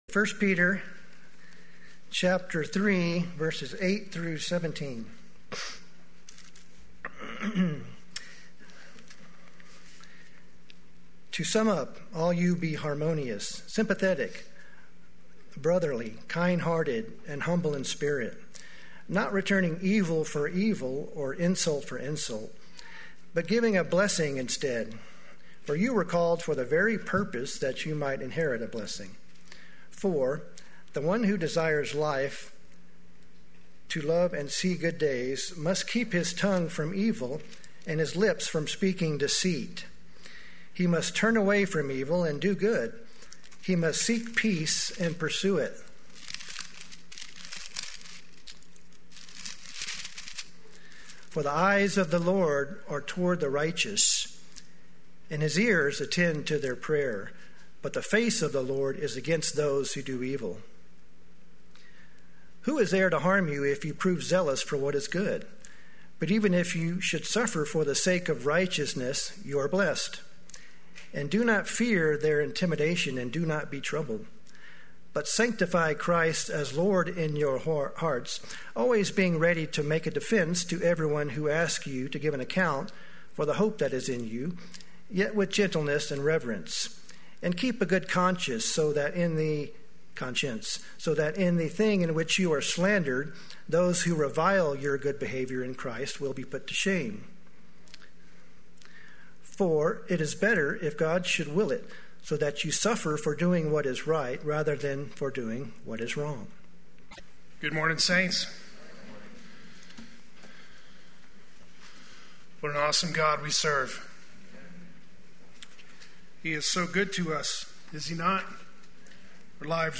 Play Sermon Get HCF Teaching Automatically.
God’s Blessing Upon the Righteous Sunday Worship